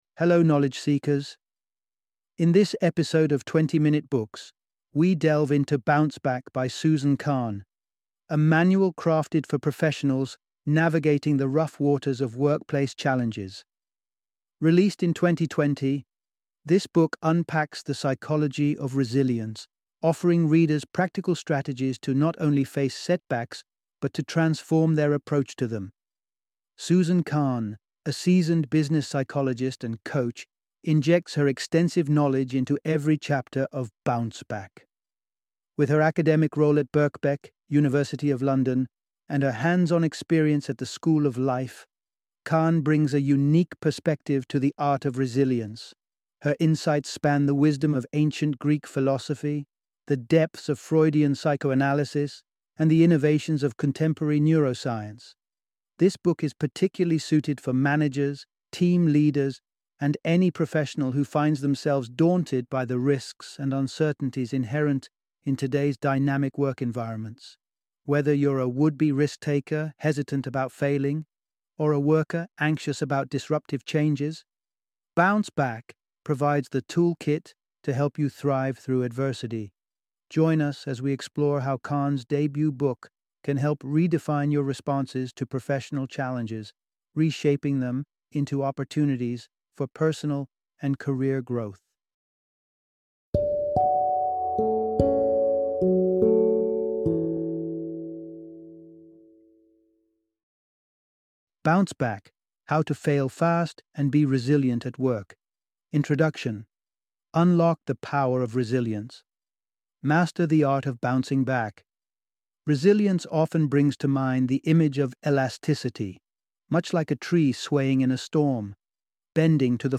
Bounce Back - Audiobook Summary